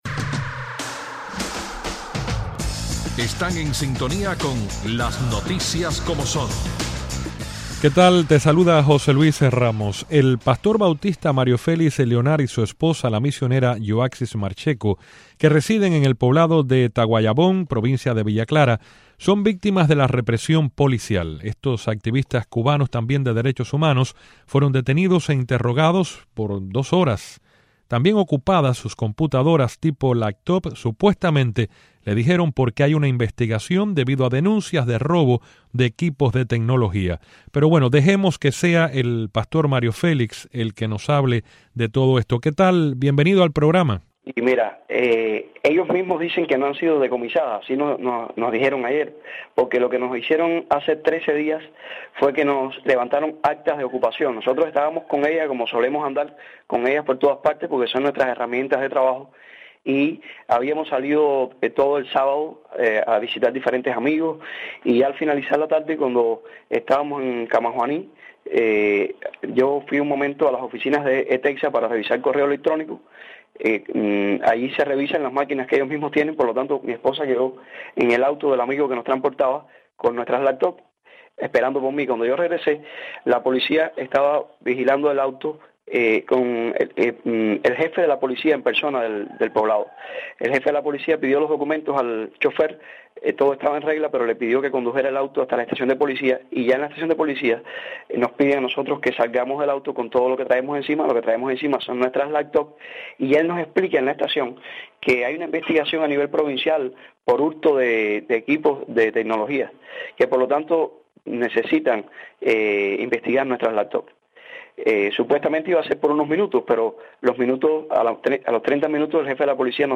También, Brasil vive la fiesta de la Copa Mundial de Futbol, pero la población se enfrenta a una inflación amenazante que ha generado un mayor descontento de la población ante la ausencia de una mejora en su calidad de vida. Desde Belem, Brasil, participa el analista político